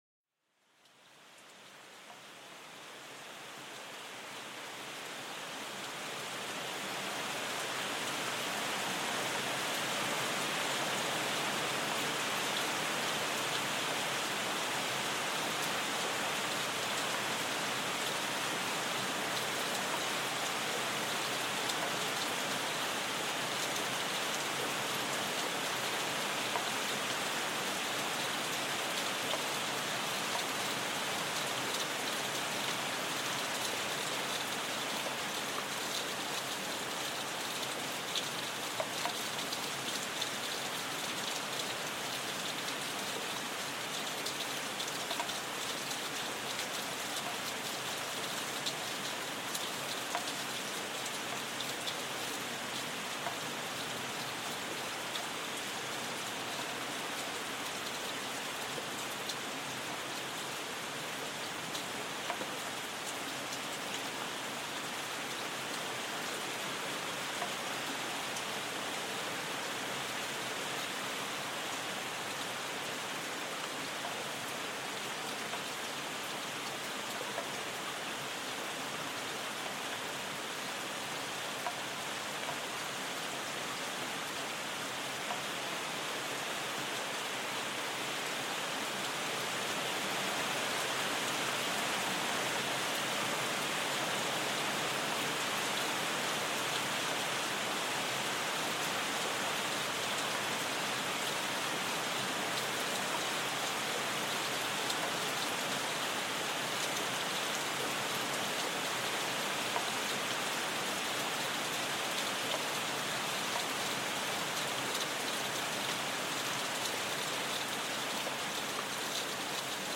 Descubre el sonido cautivador de la lluvia torrencial, una sinfonía natural que invita a la relajación. Déjate envolver por el ritmo calmante de las gotas que bailan sobre el follaje denso.